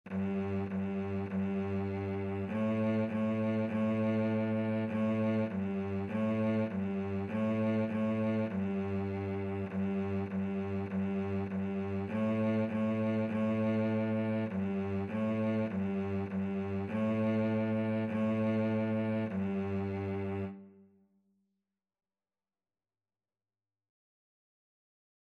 2/4 (View more 2/4 Music)
G3-A3
Cello  (View more Beginners Cello Music)
Classical (View more Classical Cello Music)